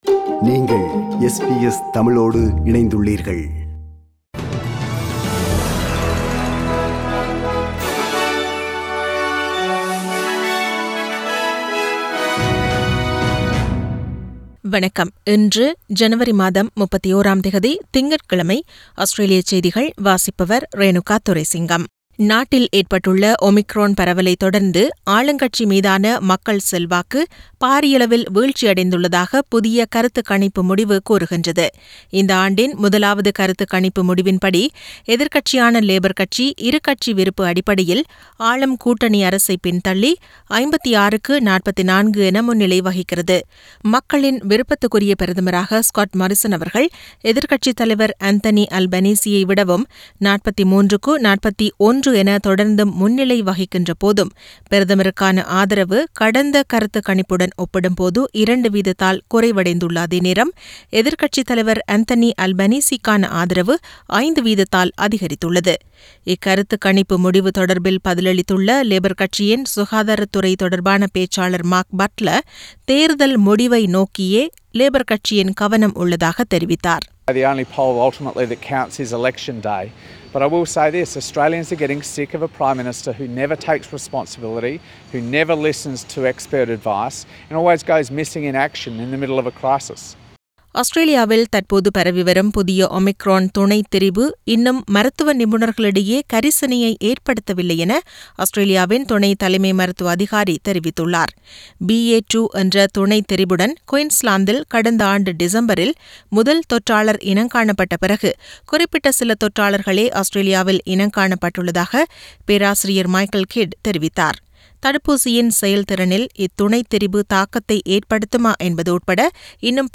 Australian news bulletin for Monday 31 Jan 2022.